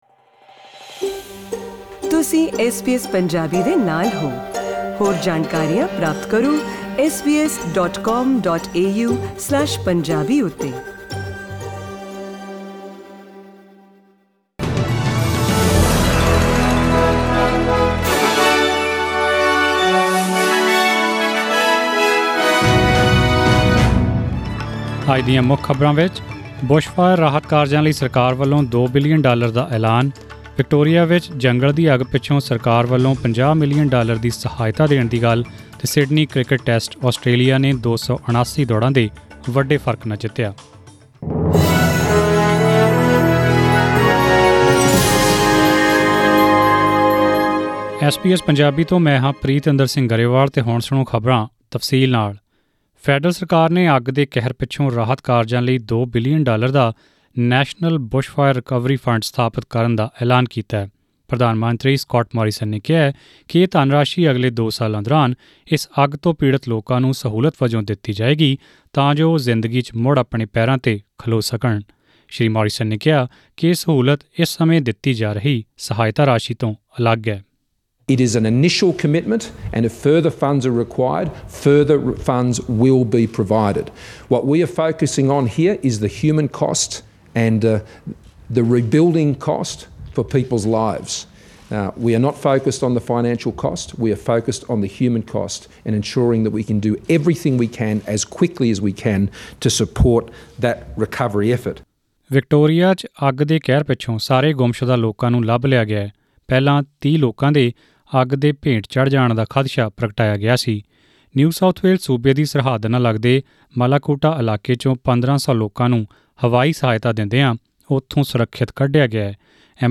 In today’s news bulletin